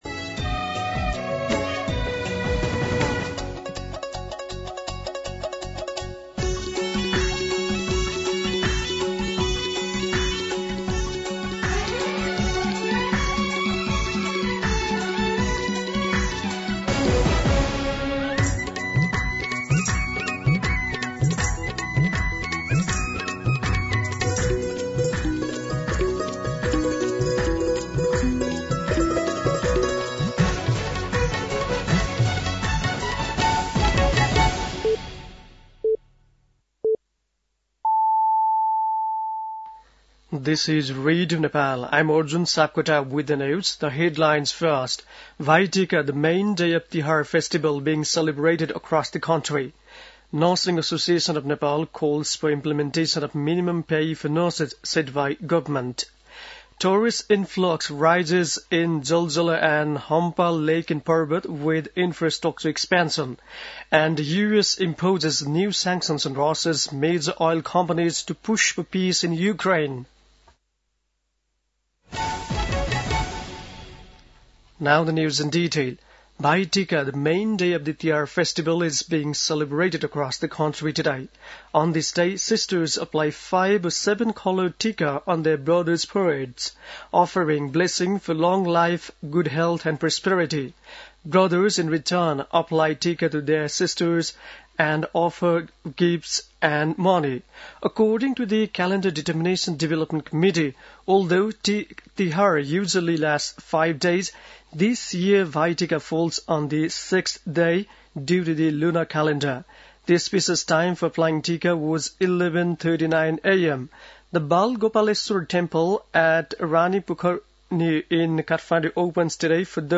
दिउँसो २ बजेको अङ्ग्रेजी समाचार : ६ कार्तिक , २०८२
2pm-English-news.mp3